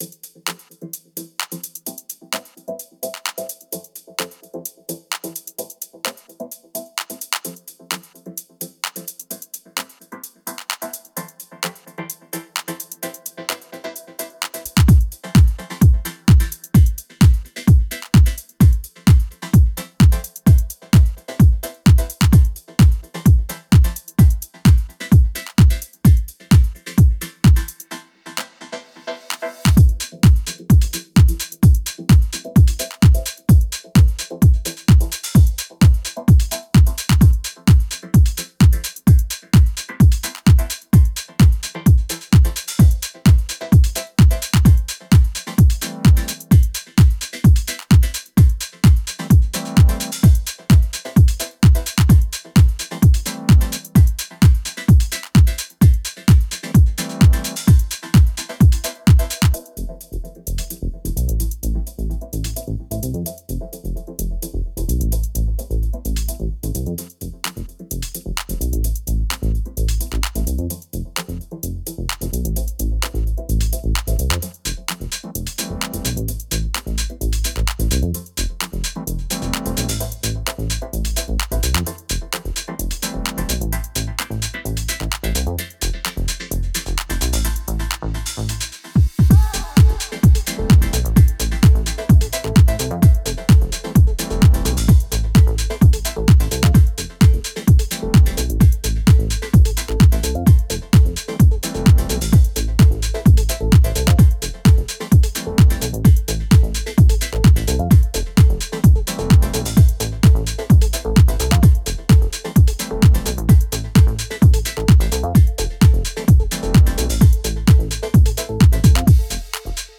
# House # Deep House